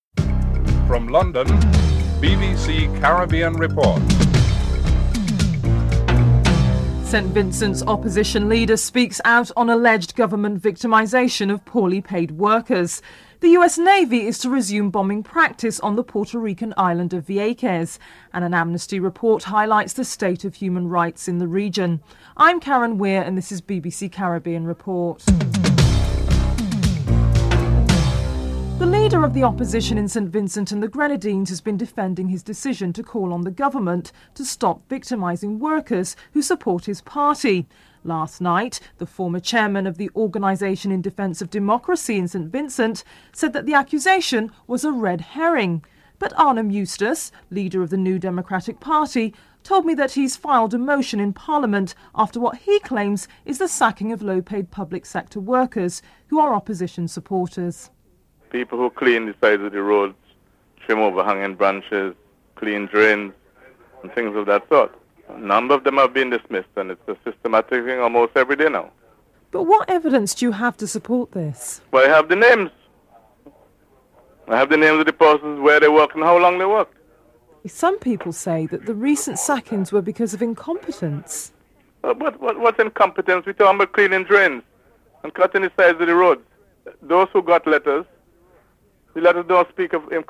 1. Headlines (00:00-00:27)
3. United States Navy is to resume bombing practice on the Puerto Rican Island of Vieques. Governor Sila Calderon is interviewed (02:59-04:23)